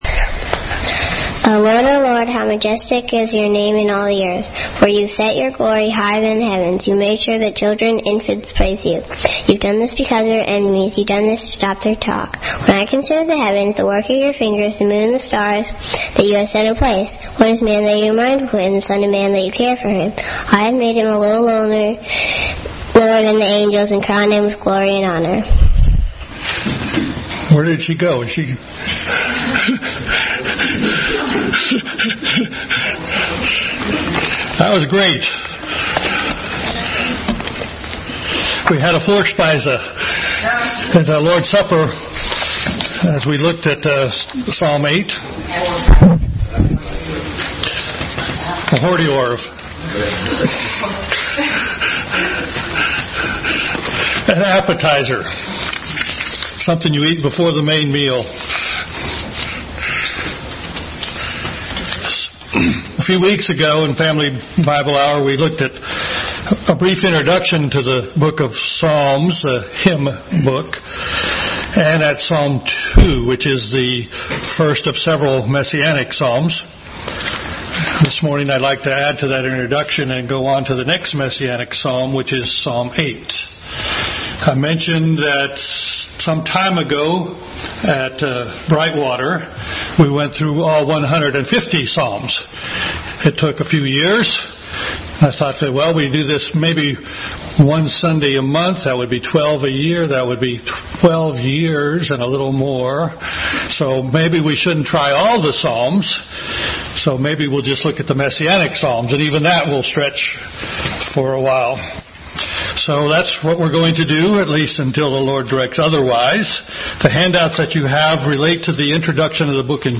Bible Text: Psalm 8 | Family Bible Hour Message.